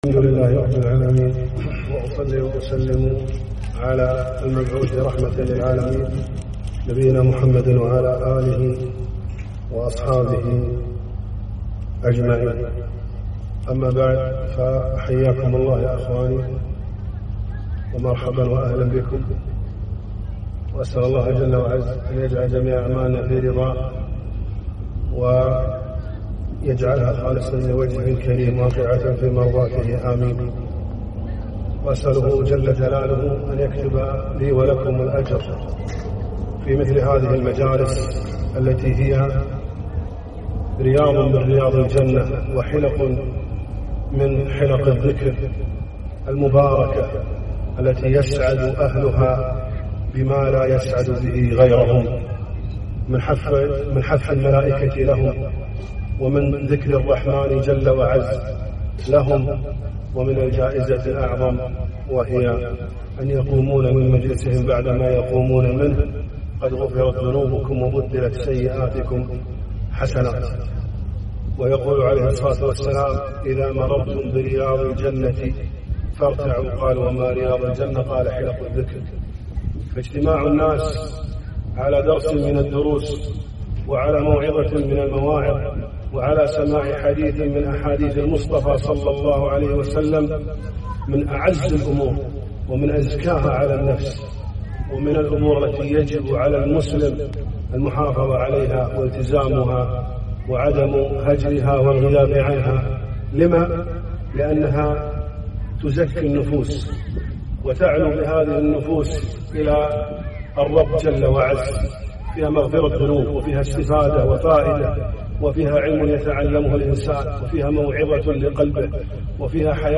محاضرة - (إن قارون كان من قوم موسى فبغى عليهم )